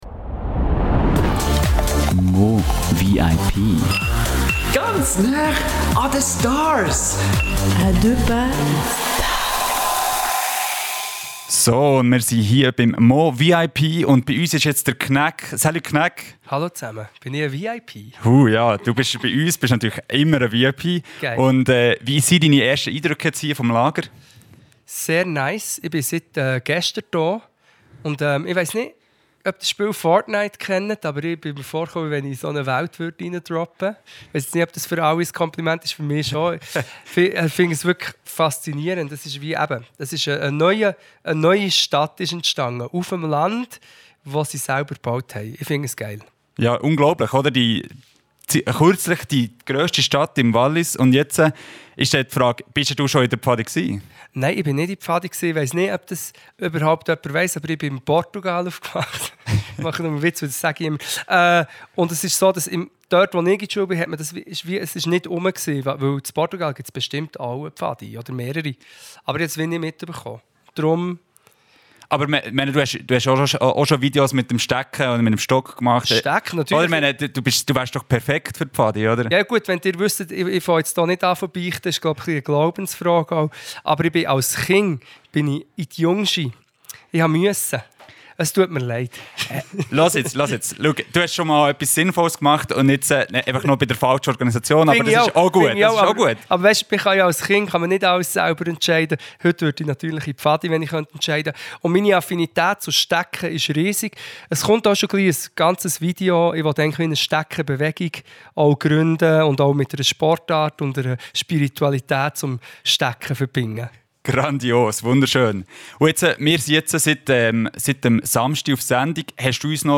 Martedì Knackeboul è stato ospite di Radio Sonar. Qui puoi ascoltare le sue prime impressioni sul mova, la sua affinità con le bacchette di legno e i consigli che ha dato alla nostra troupe radiofonica.